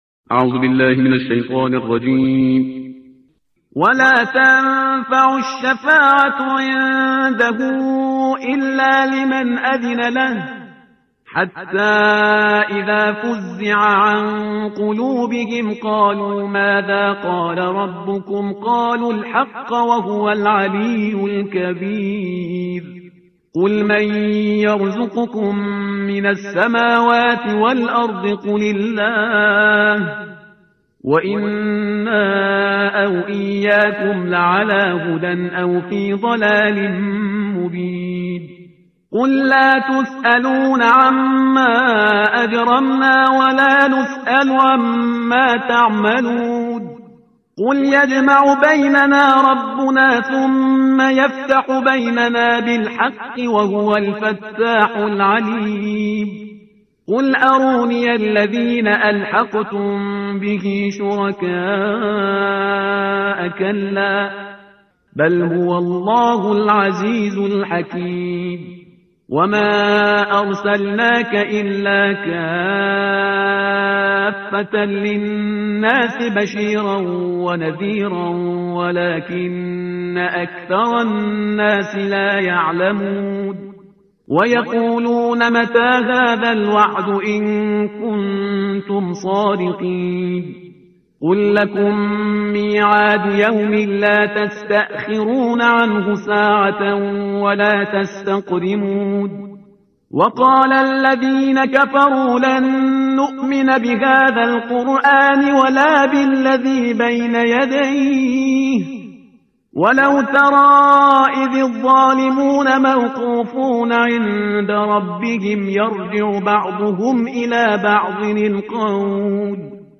ترتیل صفحه 431 قرآن با صدای شهریار پرهیزگار